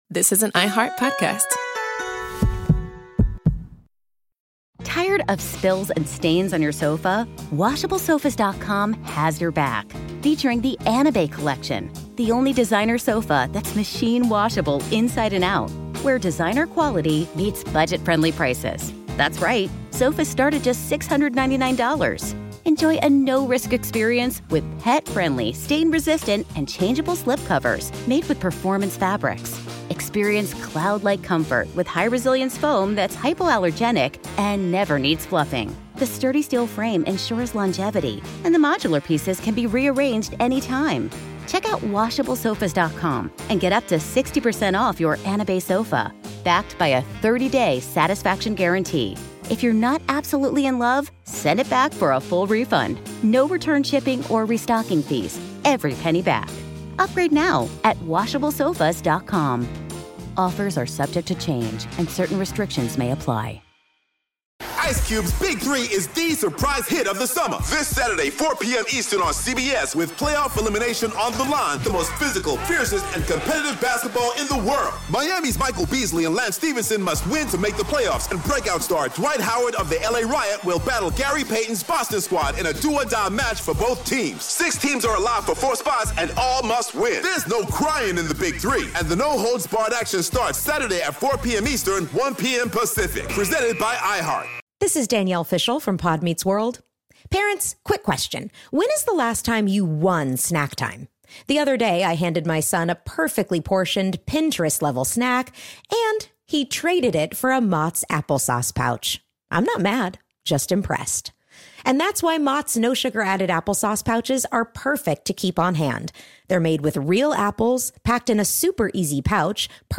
The result was the Battle of Midway, one of the most important naval victories of the war. Historian Stephen Ambrose explains how these two moments, linked by timing and strategy, helped shift the course of World War II.